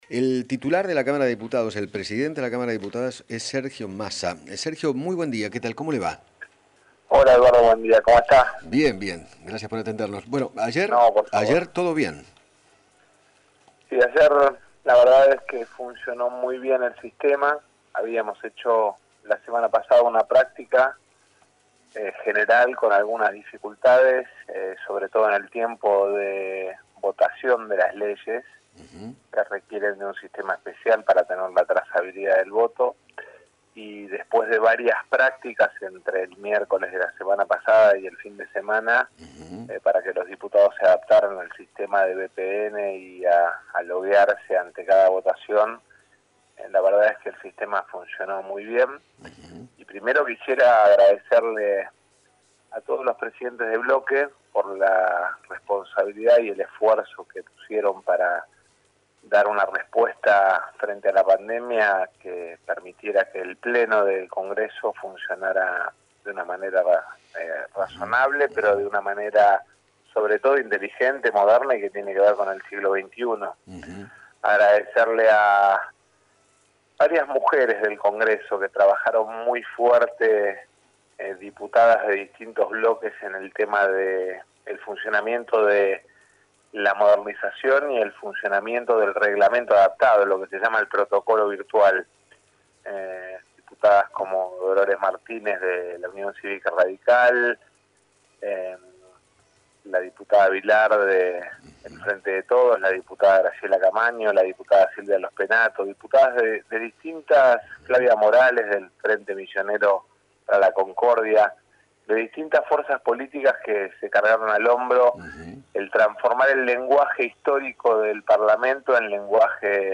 Sergio Massa, Presidente de la Cámara de Diputados, dialogó con Eduardo Feinmann sobre la primera sesión del Senado de la Nación realizada en forma virtual. Además, se refirió a la polémica liberación de presos en la Provincia de Buenos Aires.